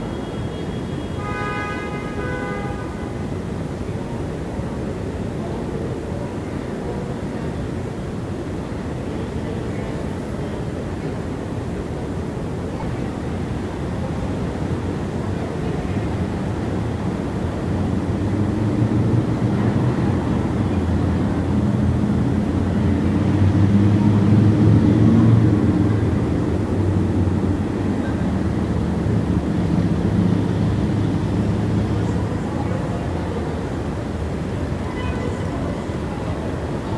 city_ambience.wav